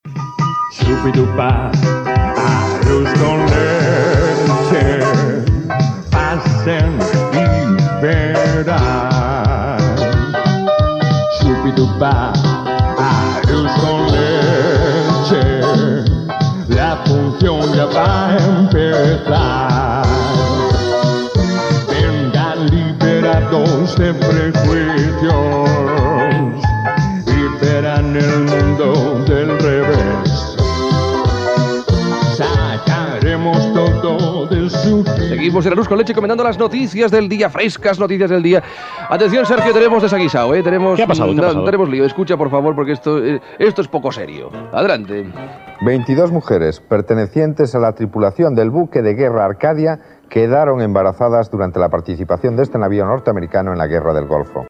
Sintonia cantada del programa, notícia sobre el vaixell de guerra Arcadia a la Guerra del Golf Pèrsic